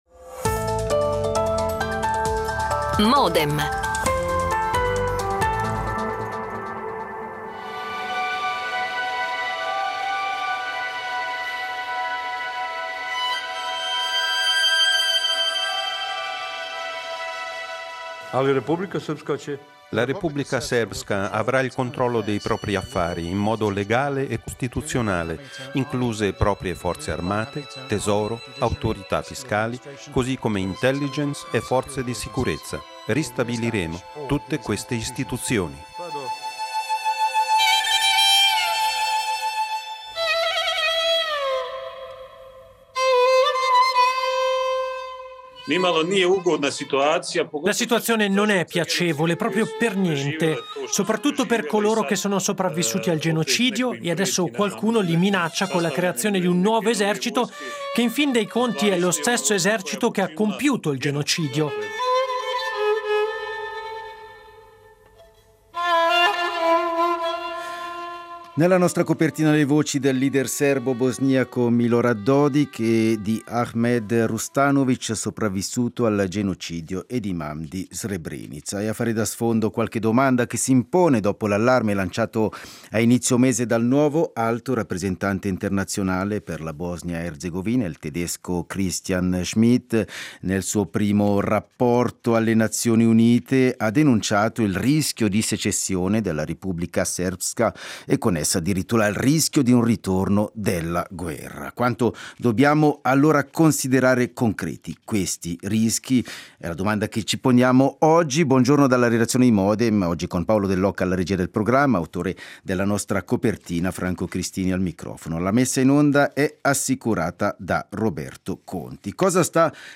diplomatico italiano, esperto di Balcani
scrittrice e giornalista bosniaca